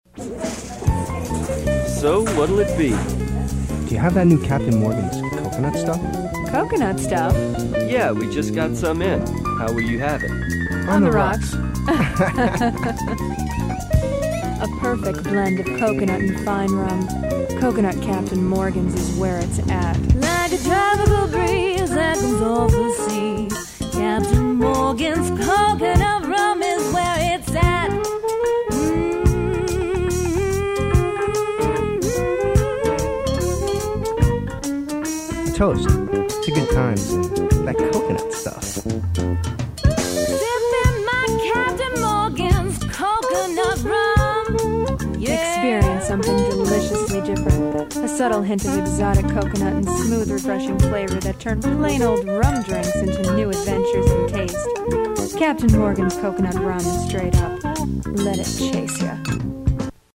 male voice-over
female voice-over
saxophone
Unknown: piano
Unknown: bass
Unknown: drums
Recorded at Berklee Studio F, Boston, MA-1994